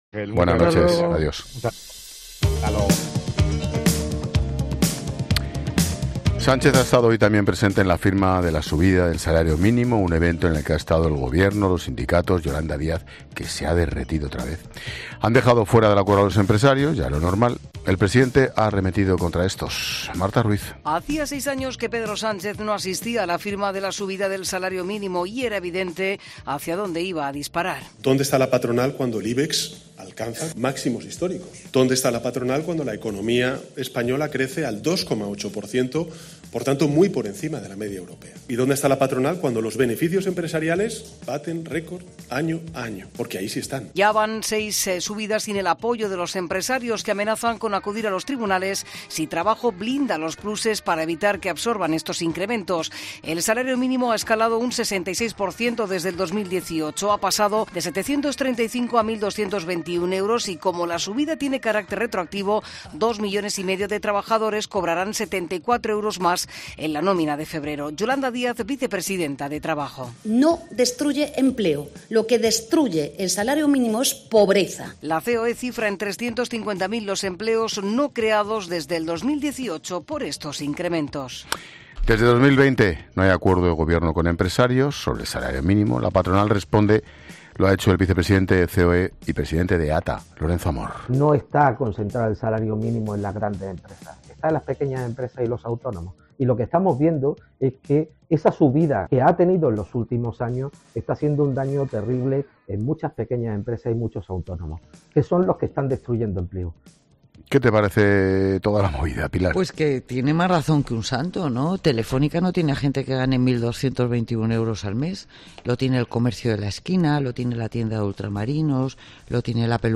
Expósito aprende en Clases de Economía de La Linterna con la experta económica y directora de Mediodía COPE, Pilar García de la Granja, sobre el aumento de las bajas laborales en España y sus causas